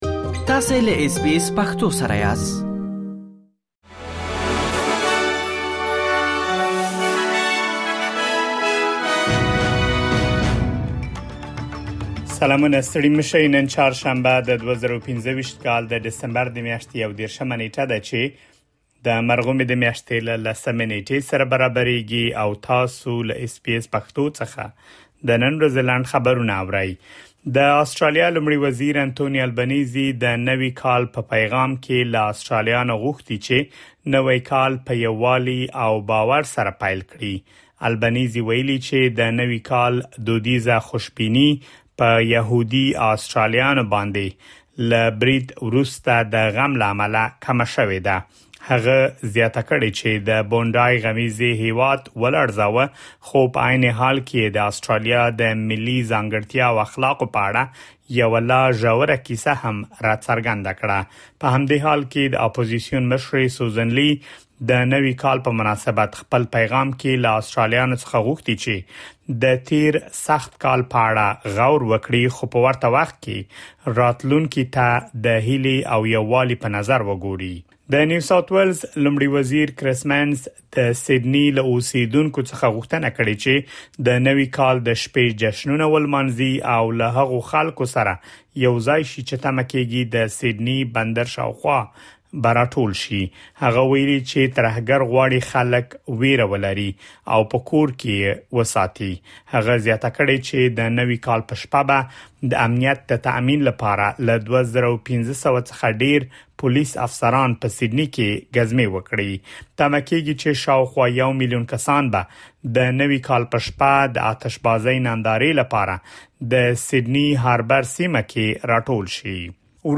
د اس بي اس پښتو د نن ورځې لنډ خبرونه |۳۱ ډسمبر ۲۰۲۵